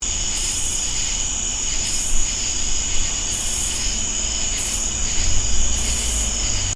Lesser angle-wing katydids
They start singing about an hour after dark, calling with short bursts of high-pitched sound. Each call is somewhat like a can of dried peas being shaken rapidly. In the following recording, the lesser-angle wings call five times. You’ll also hear the common true katydid singing “di-di-did” in the background. Crickets are also singing in this clip — they form a continuous band of sound against which the katydids play.
lesseranglewing.mp3